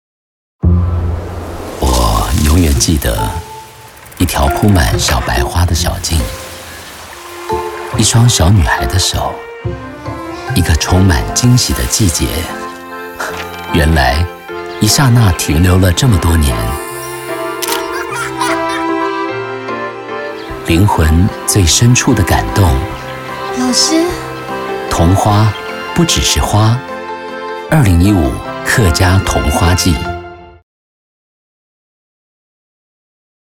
國語配音 男性配音員
廣告配音員